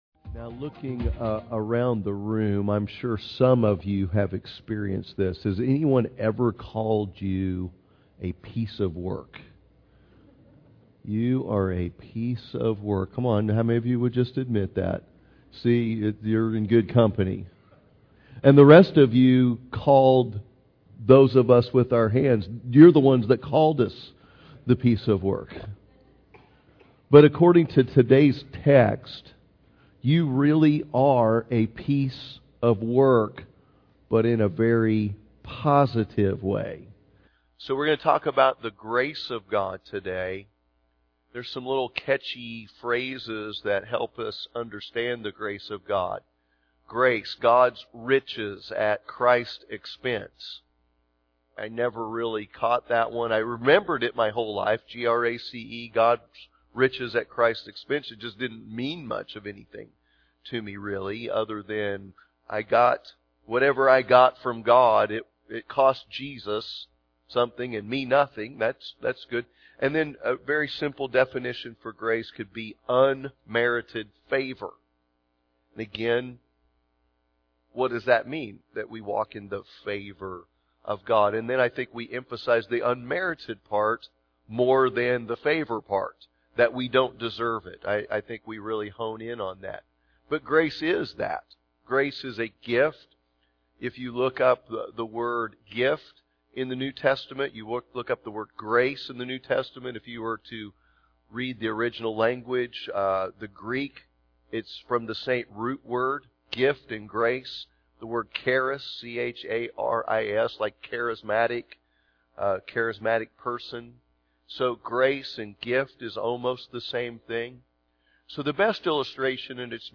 Sunday Morning Service
Sermon